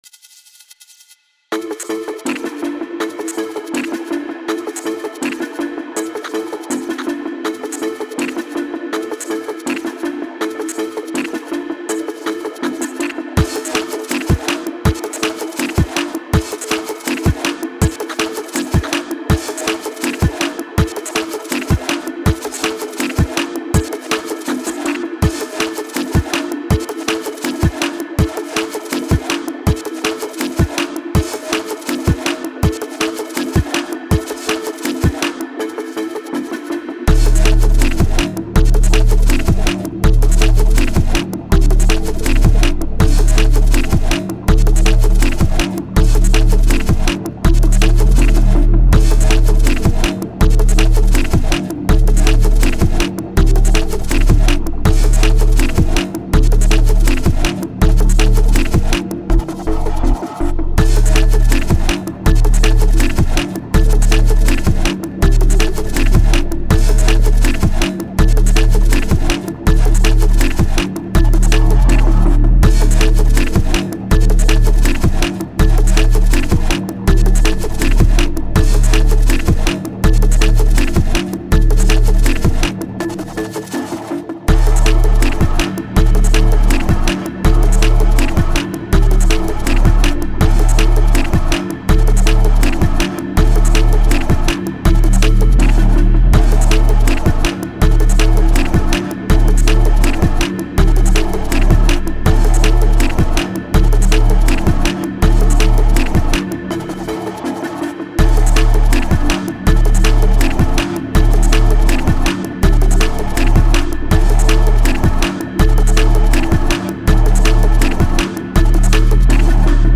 DEEP DNB